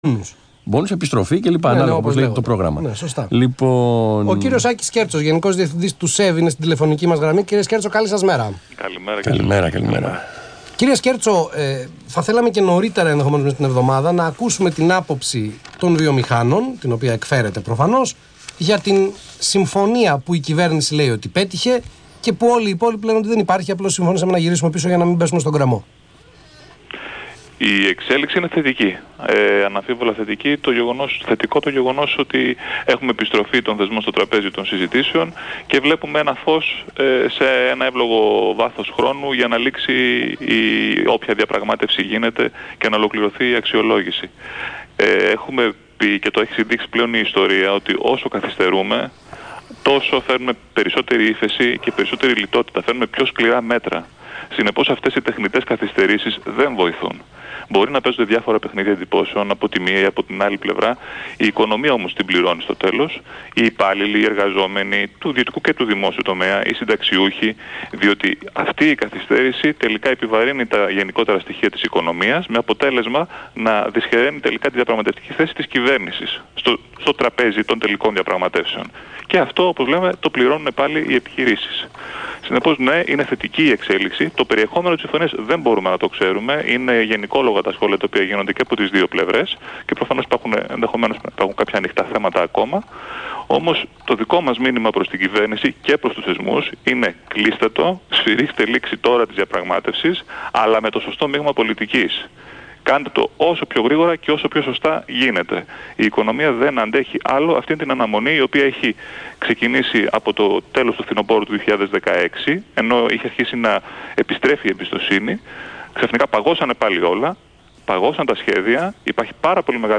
Συνέντευξη του Γενικού Διευθυντή του ΣΕΒ, κ. Άκη Σκέρτσου στον Ρ/Σ Αθήνα 9.84, 24/2/2017